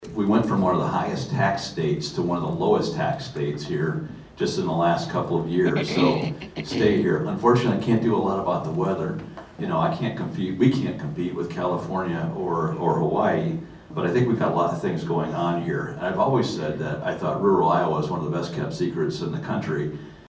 The Carroll Chamber of Commerce and Carroll County Growth Partnership (CCGP) hosted its third legislative forum of the 2026 session on Saturday, and the future of Iowa’s workforce was one of the questions brought to District 6 Sen. Jason Schultz (R-Schleswig) and District 11 Rep. Craig Williams (R-Manning).